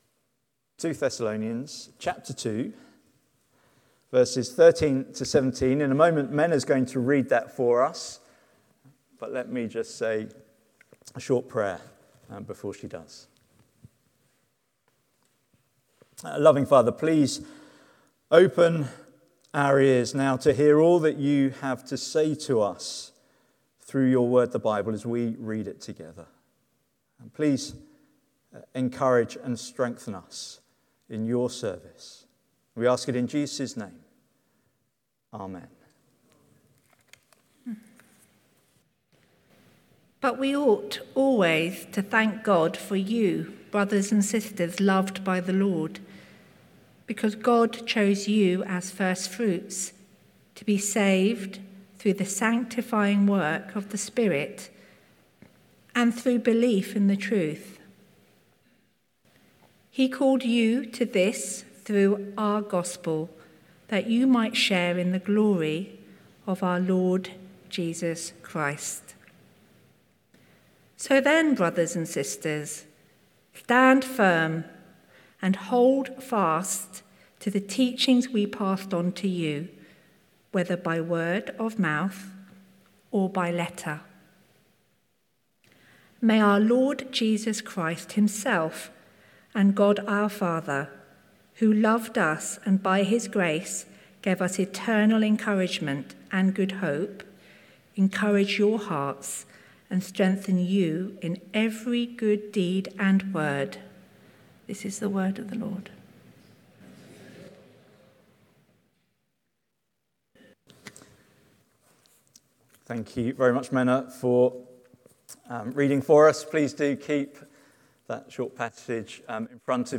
Sermon Transcript Study Questions